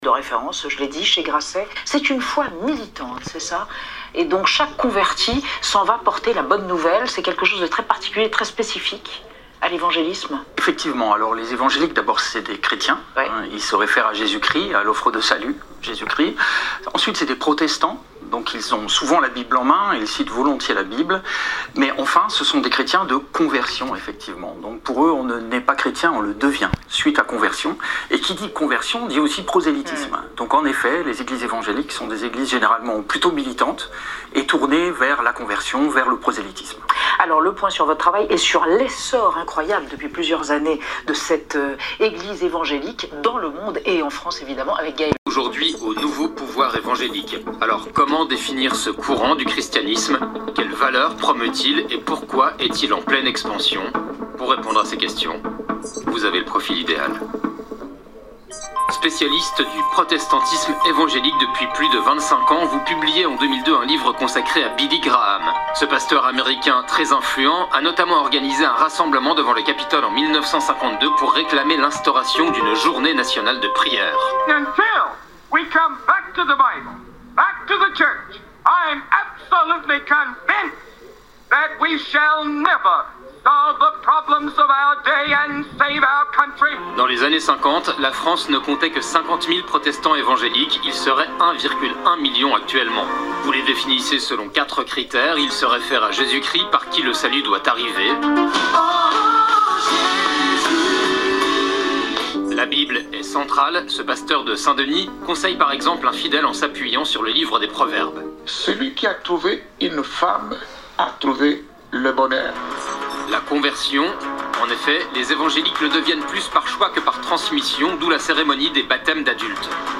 Le 12 février, à la radio, elle revenait sur le succès inattendu de son livre "Résister" qui documente les dangers de l’Extrême Droite en France et à l’étranger avec le conseil " Ne restez pas dans l'impuissance, la sidération, l'inaction ". Son interview :